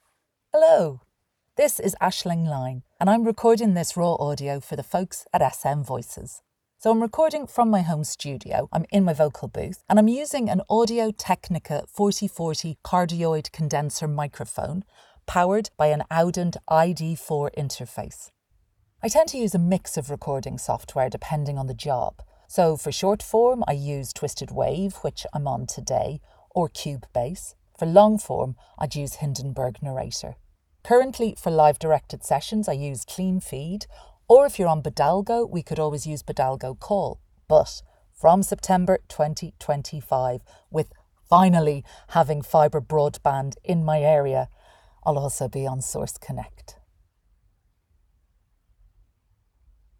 Authentic, Engaging, Enticing